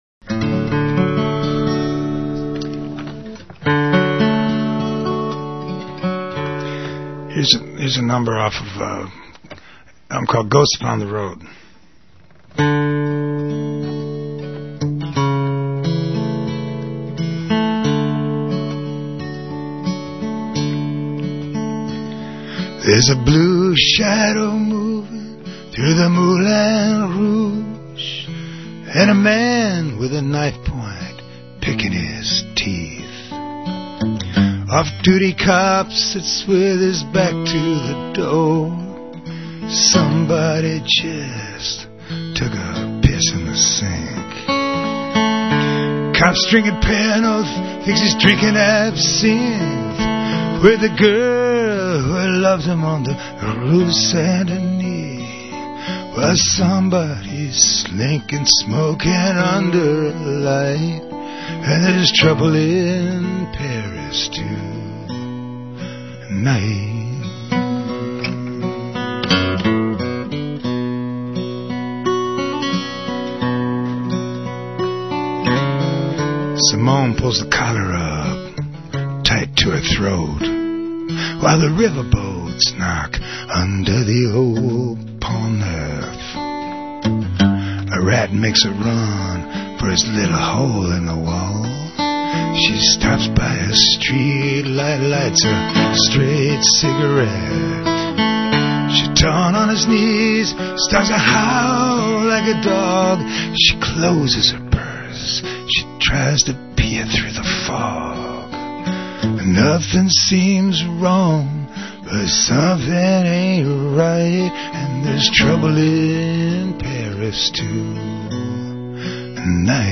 live songs (from radio)
mono